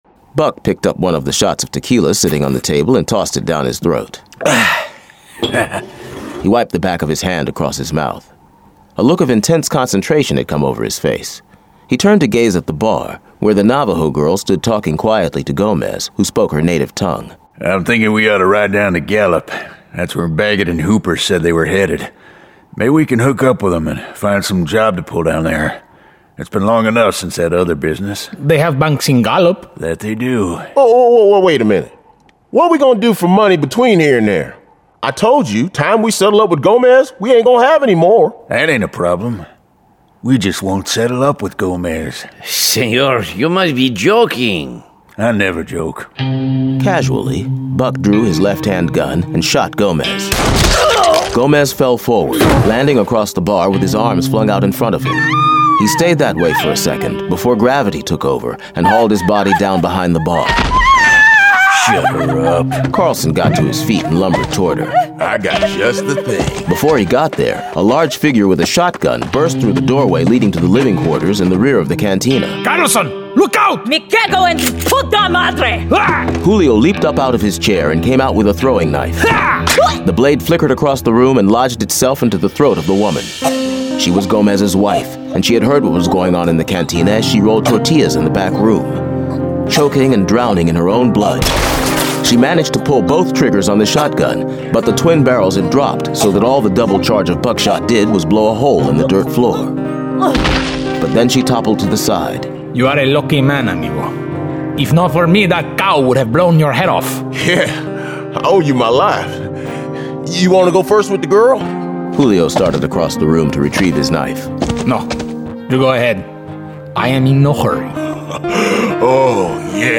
Loner 1: Loner [Dramatized Adaptation]